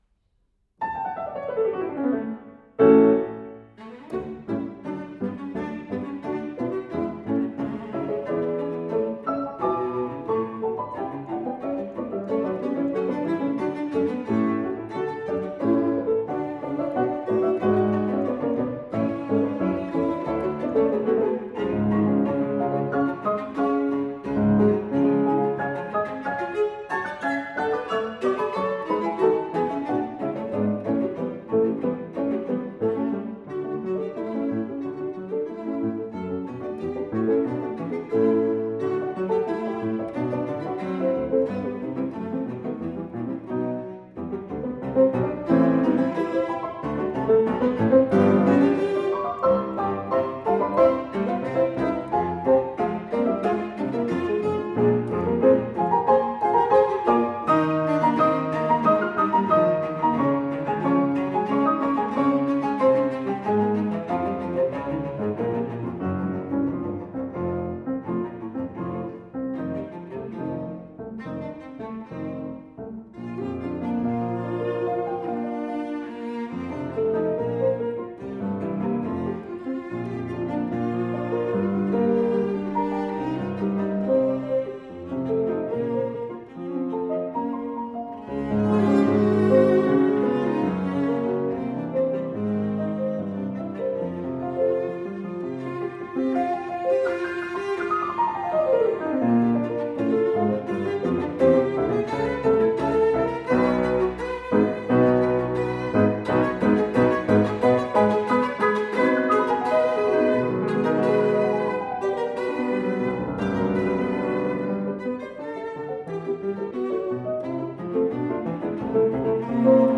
Hans Huber: II Allegretti grazioso e umoristico